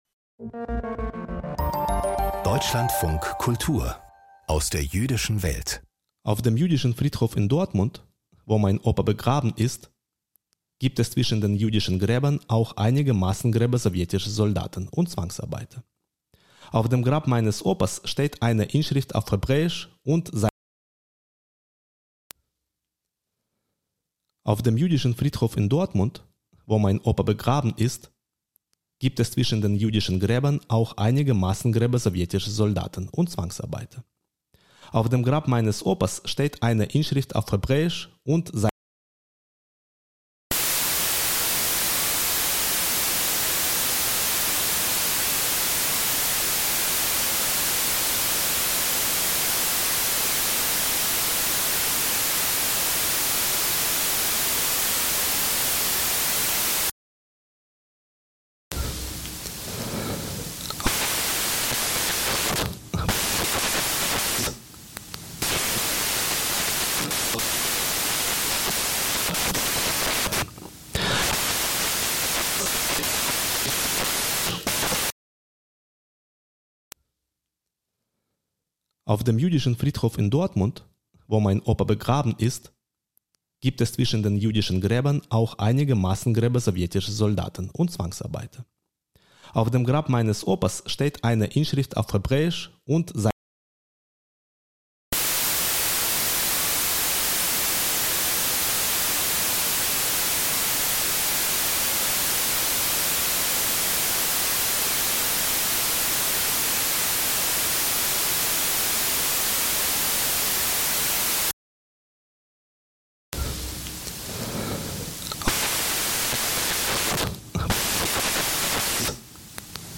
Glosse: Hemmungslose Sieger